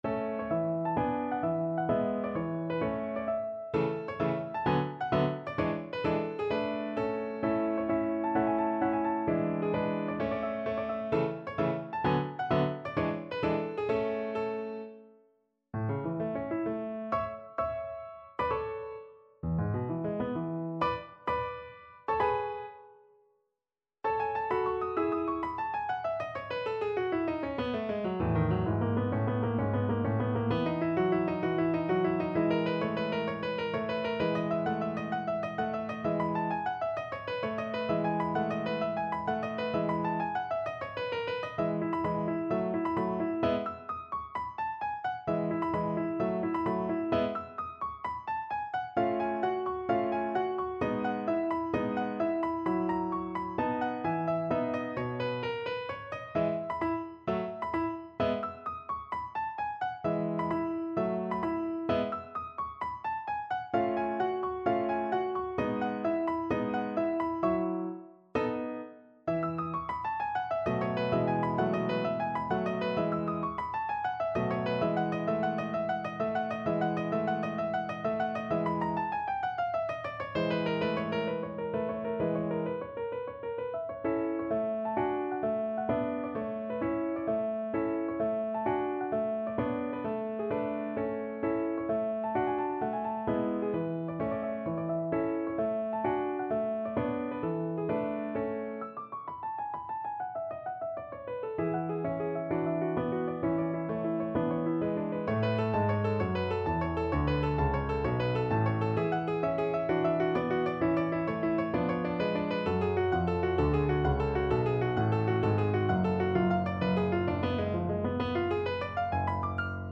No parts available for this pieces as it is for solo piano.
Piano  (View more Intermediate Piano Music)
Classical (View more Classical Piano Music)